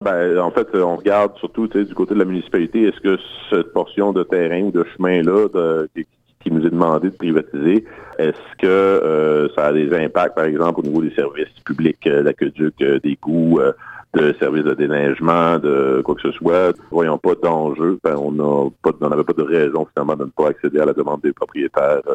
Les explications du maire Antonin Valiquette: